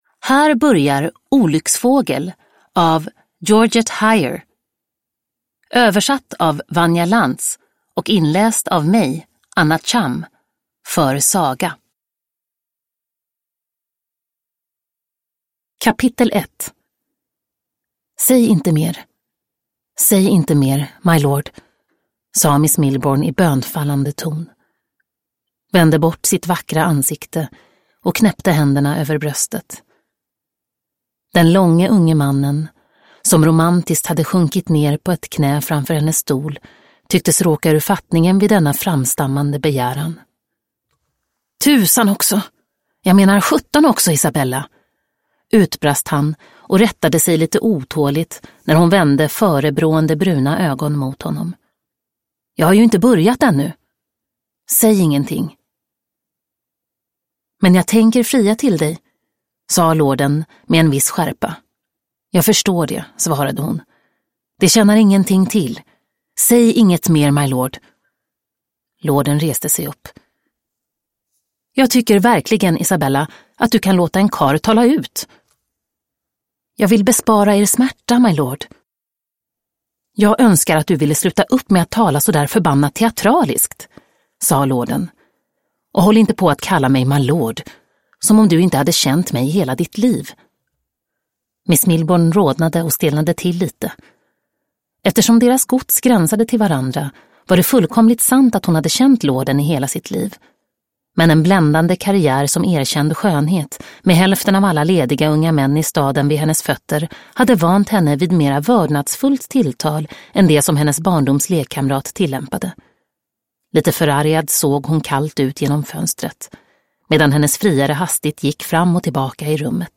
Olycksfågel – Ljudbok